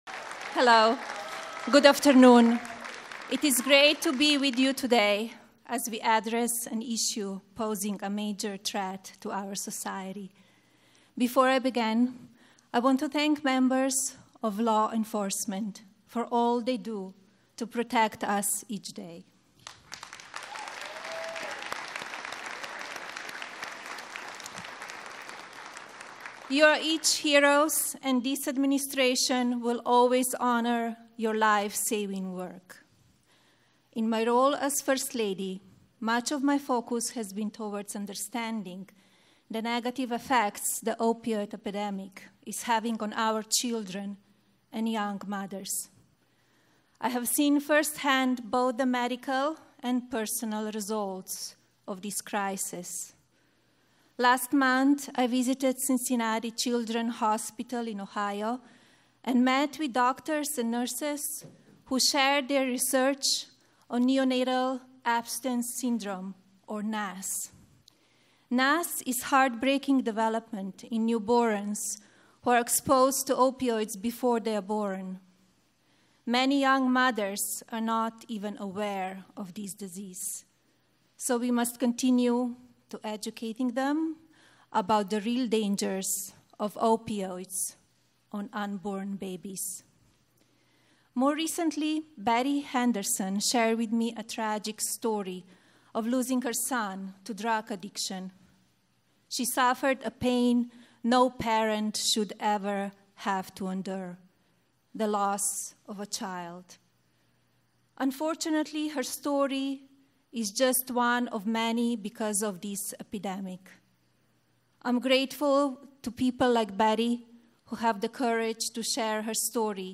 President_Trump_Delivers_Remarks_on_Combating_the_Opioid_Crisis.mp3